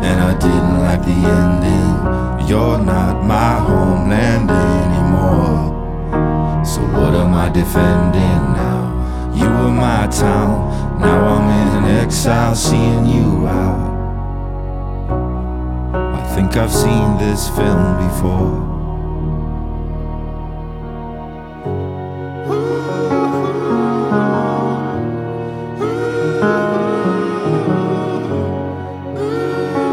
• Alternative
honeyed vocals